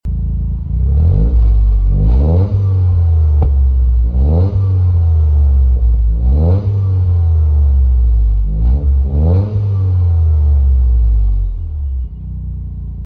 • CSK Titanium Exhaust
• 1.6L Inline 3-Cylinder Turbocharged Petrol Engine
toyota-yaris-gr-circuit-pack-turbo-sequential-pure-white-dtuk-stage-1-elc-Sound-clip.mp3